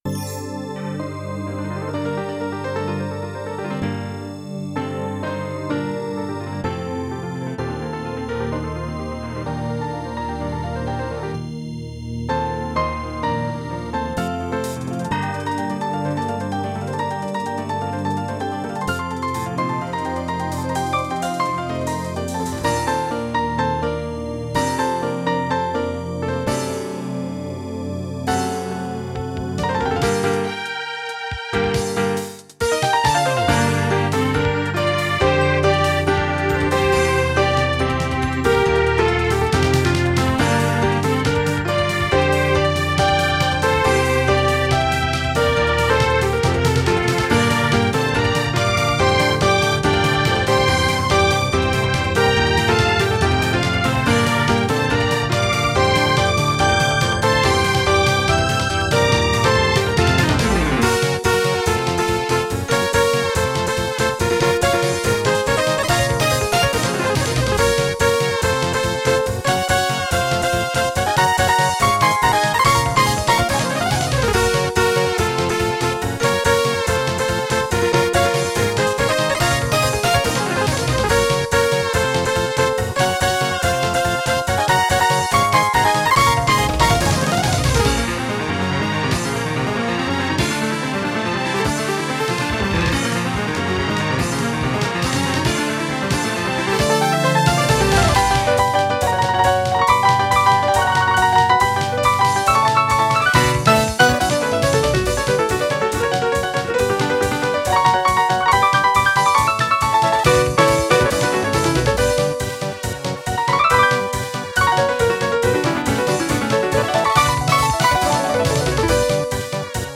使用音源为SC-D70。